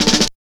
51 SN BUZZ-L.wav